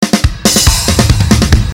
描述：摇滚鼓循环，速度140bpm。
Tag: 140 bpm Rock Loops Drum Loops 295.07 KB wav Key : Unknown